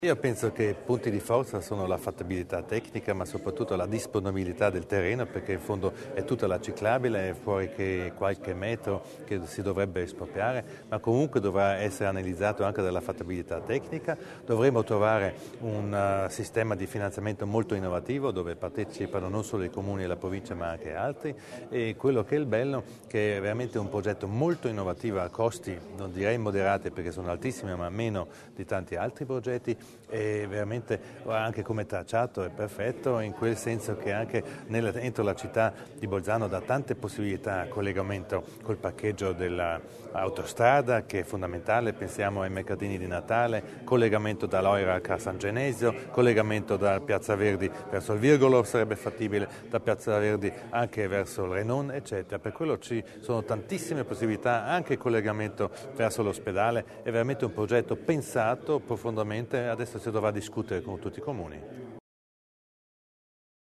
L’Assessore Widmann spiega i punti di forza del progetto